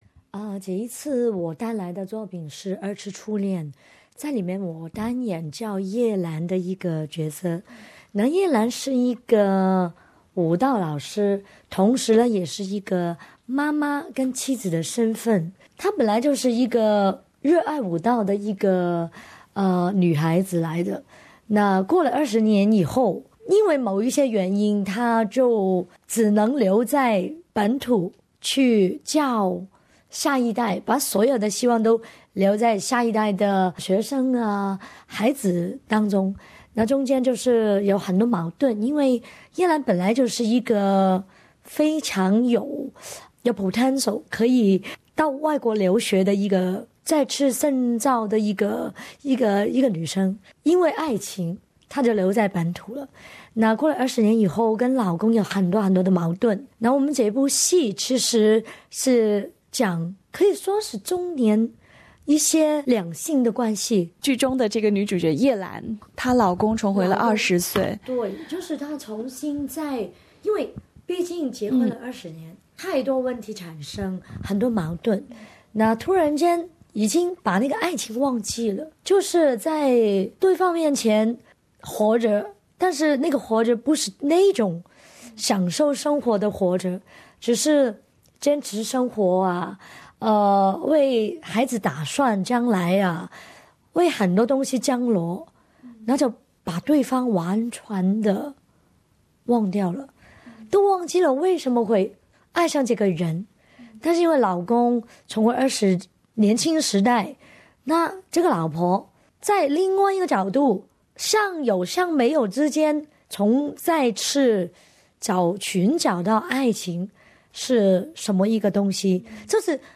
【SBS专访】华语电影节金龙奖最受欢迎女主角朱茵